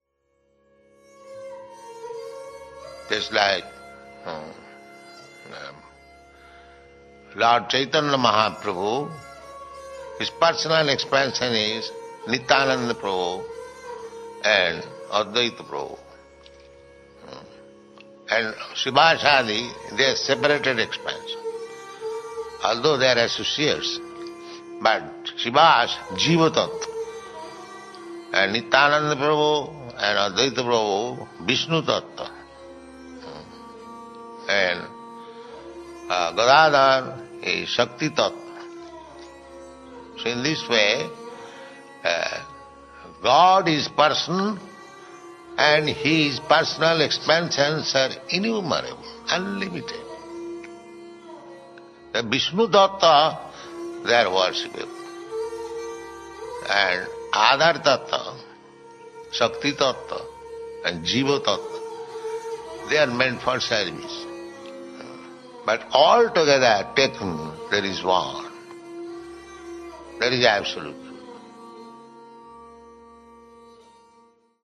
(721002 - Lecture SB 01.03.27 - Los Angeles)